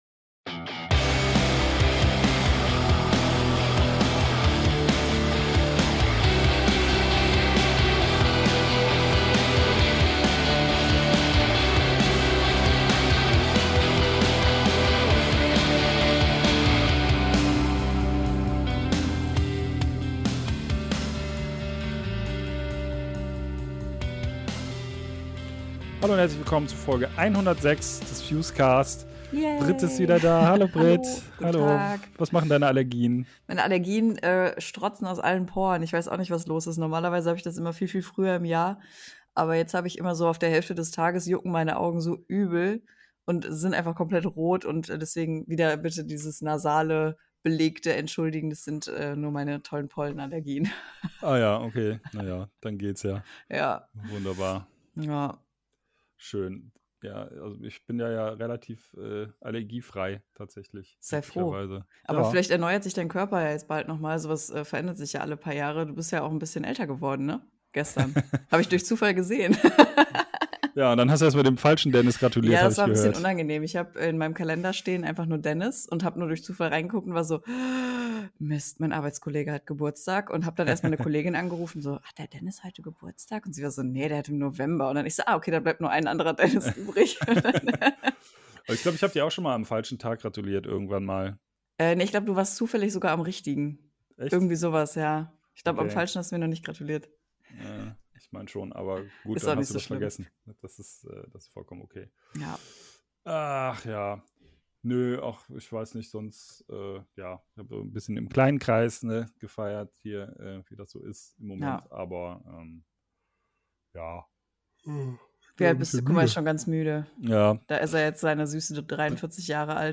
DONOTS Interview Teil 2